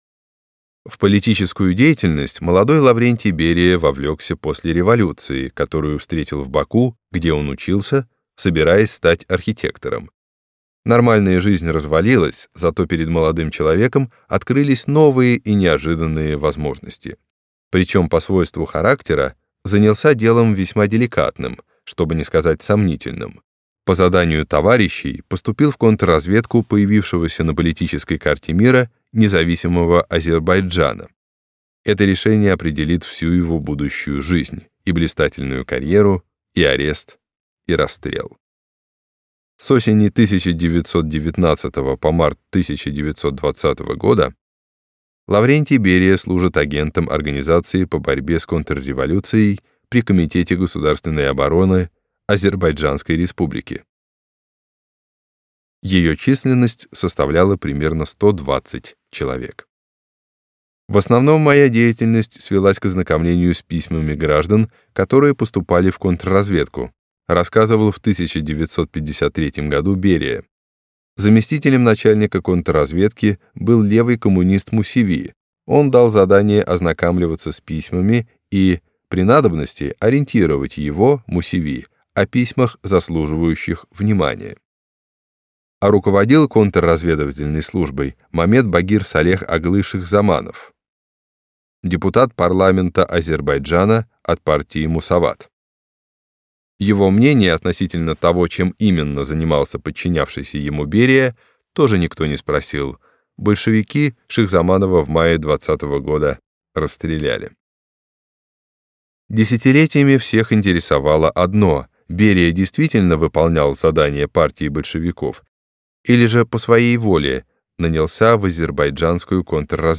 Аудиокнига Силовики. Лаврентий Берия | Библиотека аудиокниг